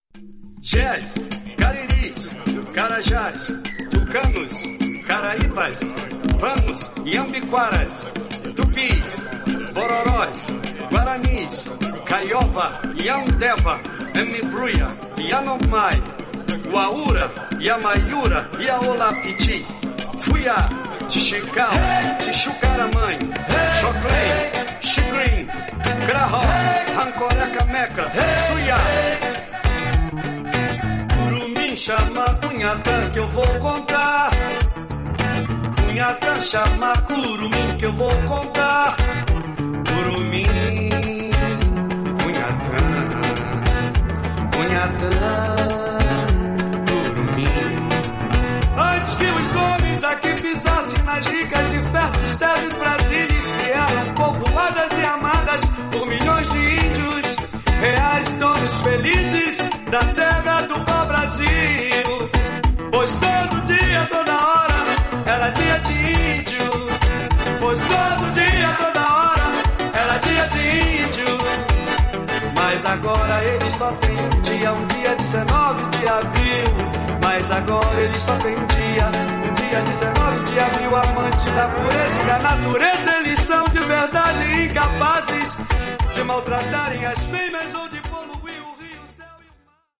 バレアリックな音源に焦点を当てた人気シリーズ